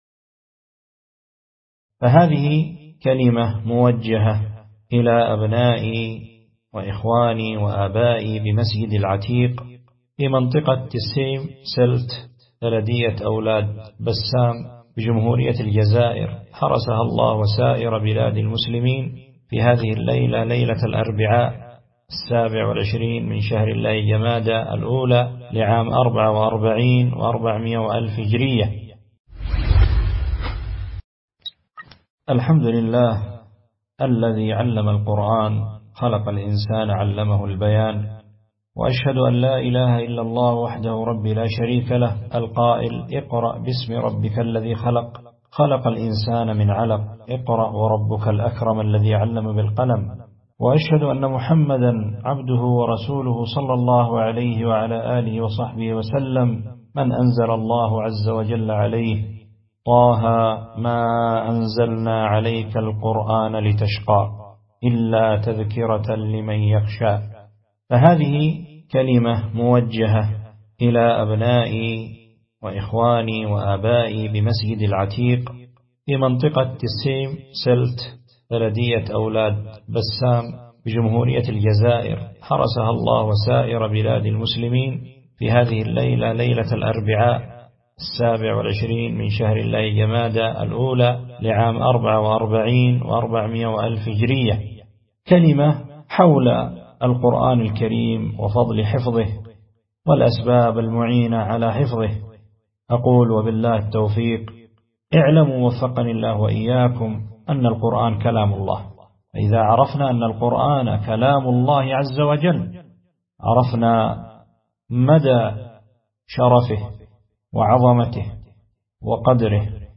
كلمة توجيهية إلى الأخوة بمسجد العتيق تسيمسلت بلدية أولاد بسام بجمهورية الجزائر